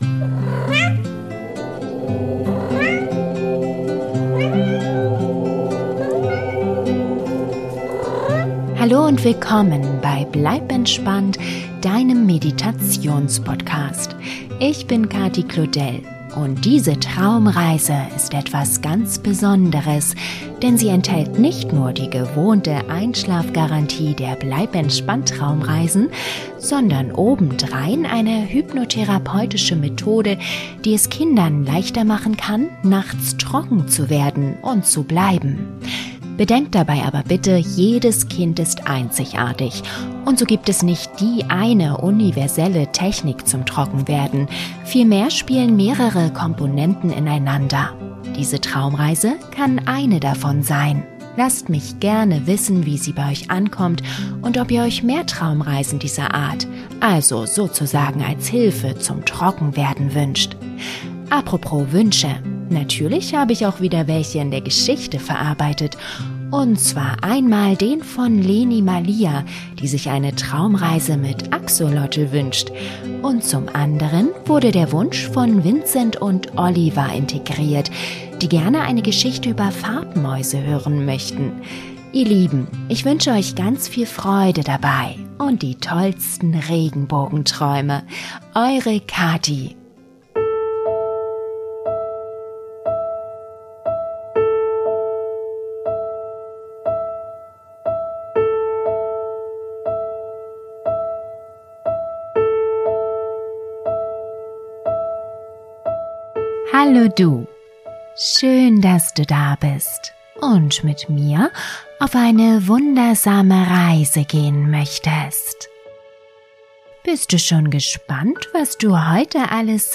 Für alle anderen Kinder ist sie ein kunterbuntes Abenteuer, das sanft beruhigt und mit wunderschönen Bildern im Kopf geborgen einschlafen lässt.